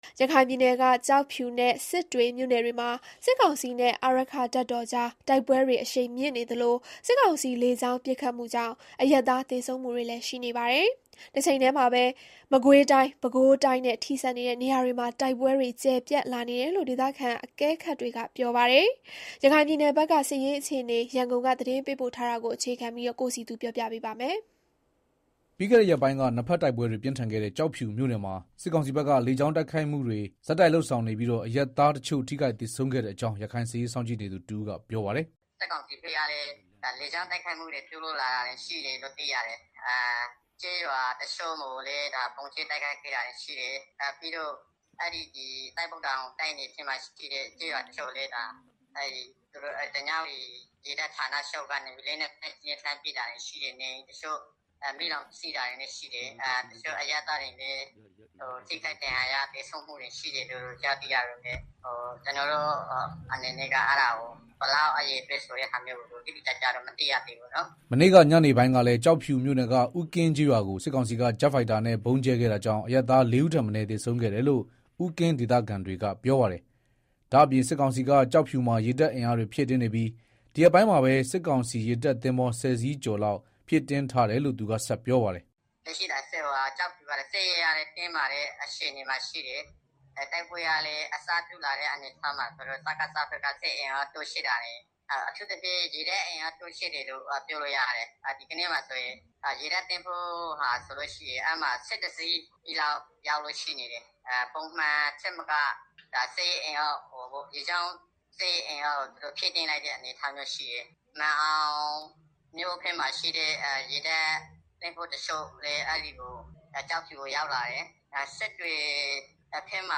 ရခိုင်ပြည်နယ်က ကျောက်ဖြူနဲ့စစ်တွေမြို့နယ်တွေမှာ စစ်ကောင်စီနဲ့ အာရက္ခတပ်တော်ကြား တိုက်ပွဲတွေအရှိန်မြင့်နေသလို စစ်ကောင်စီလေကြောင်းပစ်ခတ်မှုကြောင့် အရပ်သား သေဆုံးမှု တွေလည်းရှိနေပါတယ်။ တချိန်တည်းမှာပဲ မကွေးတိုင်း၊ ပဲခူးတိုင်းနဲ့ ထိစပ်နေတဲ့ နေရာတွေမှာပါ တိုက်ပွဲတွေ ကျယ်ပြန့်လာနေတယ်လို့ ဒေသခံအကဲခတ်တွေက ပြောပါတယ်။ ရခိုင်ပြည်နယ် စစ်ရေးအခြေအနေ ရန်ကုန်က သတင်းပေးပို့ထားတာကို တင်ပြထားပါတယ်။